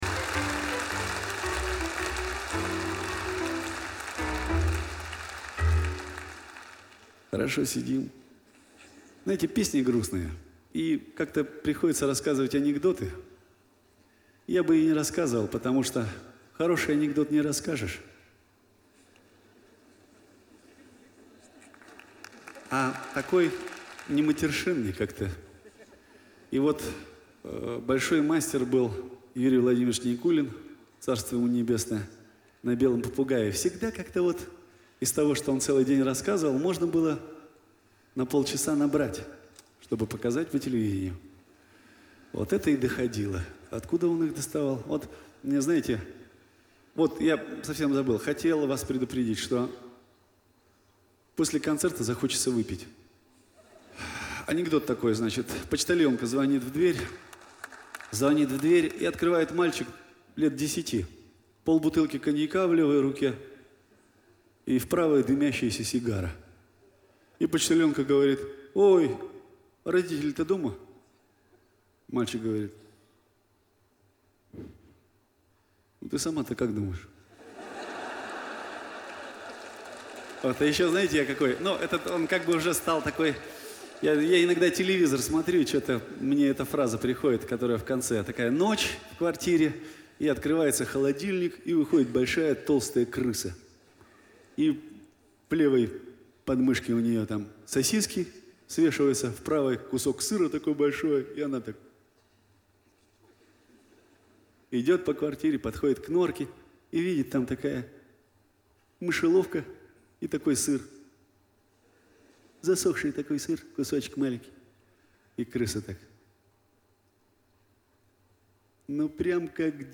До кучи - Анекдоты от Митяева